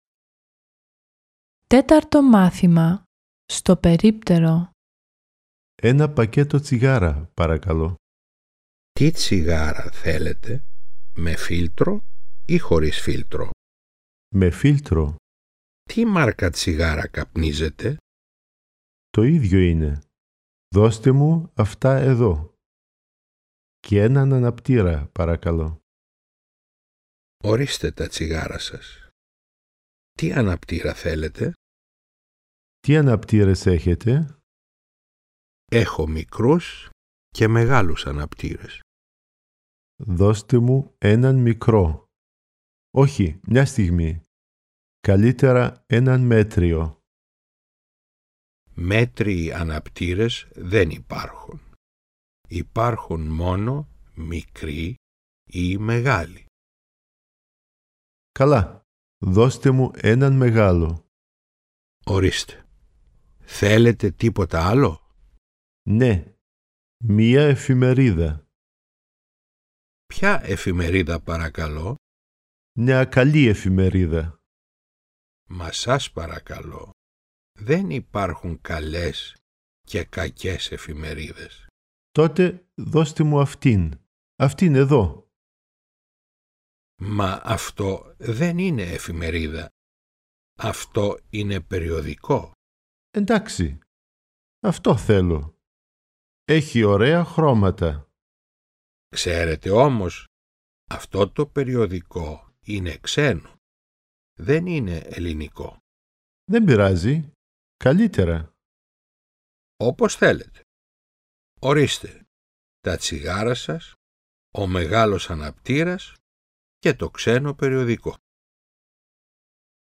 Enthält die Dialoge der Lektionen 1-20 sowie die einführenden Übungen aus dem Lehr- und Arbeitsbuch "Griechisch Aktiv"